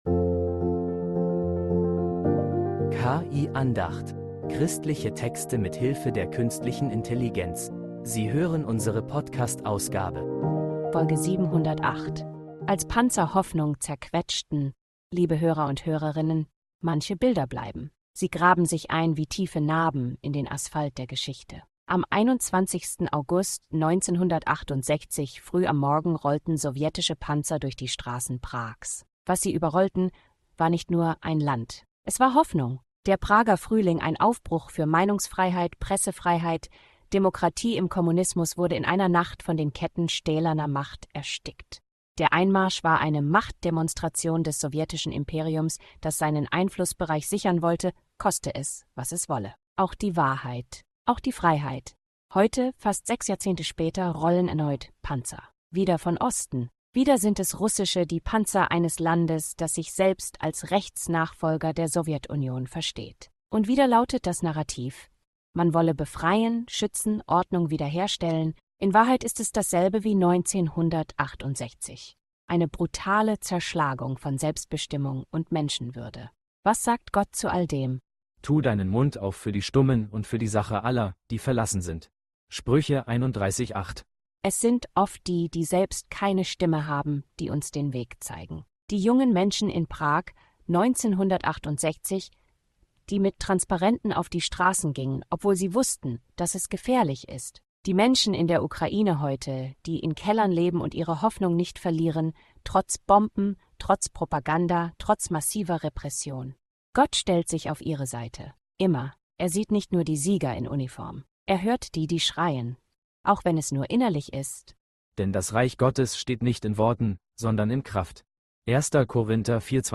Eine Andacht über Mut, Wahrheit und den unzerstörbaren Wert des Menschseins